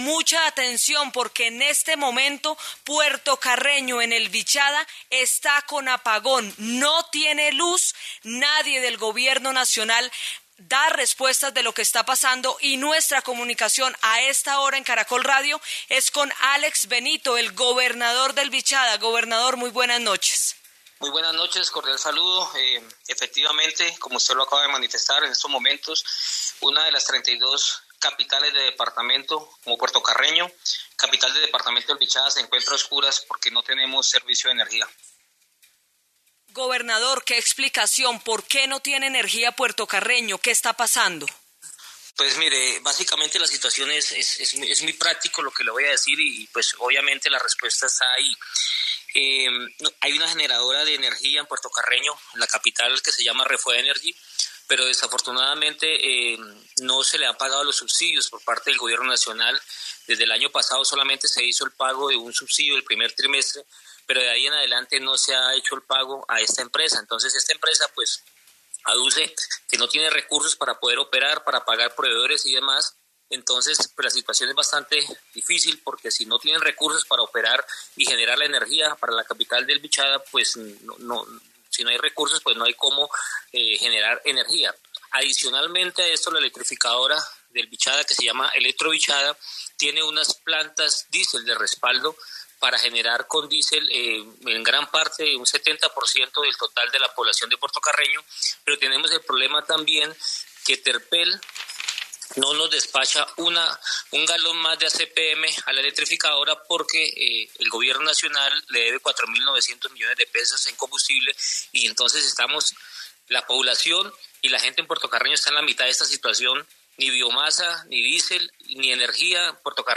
En diálogo con Caracol Radio el gobernador del departamento de Vichada, Alex Benito, reportó un apagón total en la capital Puerto Carreño, que ha afectado a cerca de 45 mil personas.